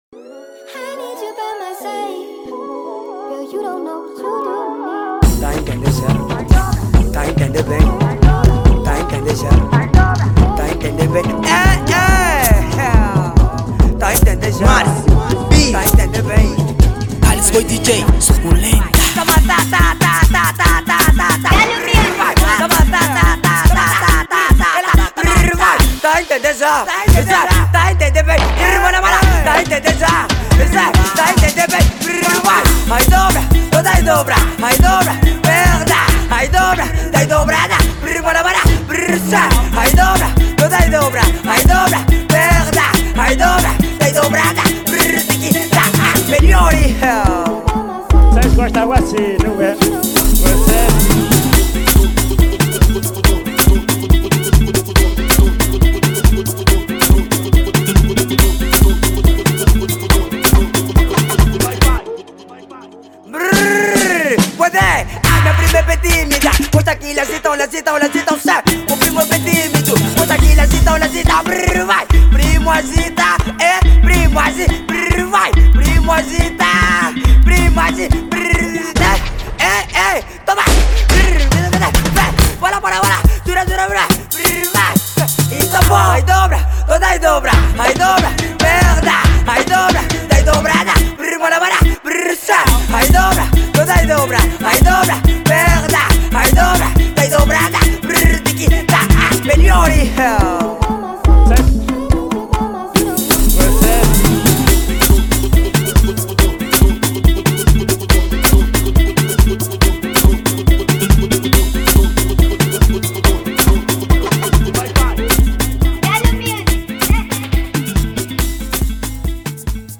Kuduro Ano de Lançamento